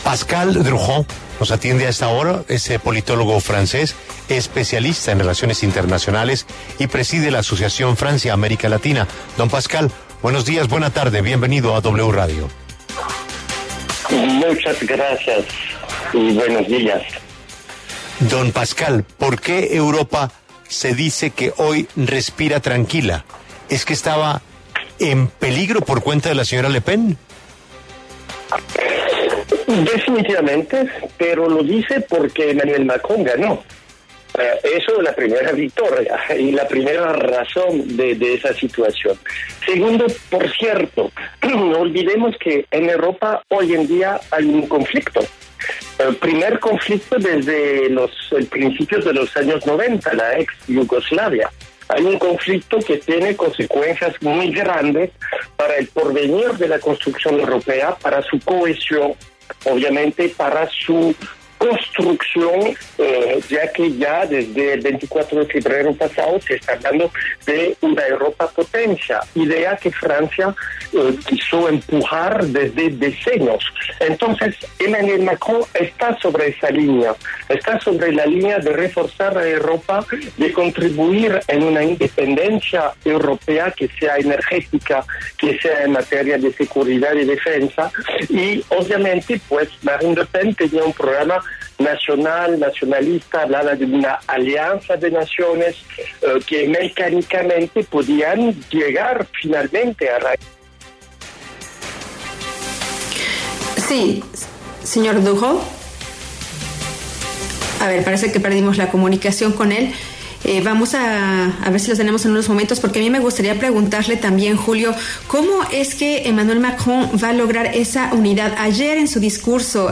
politólogo francés